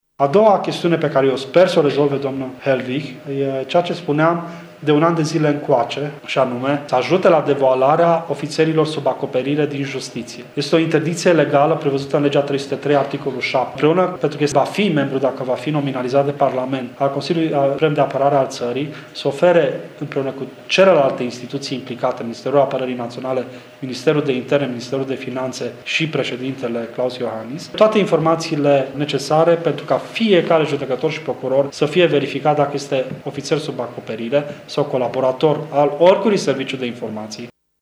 Horaţius Dumbravă a spus că a doua problemă este legată de devoalarea ofiţerilor sub acoperire din justiţie: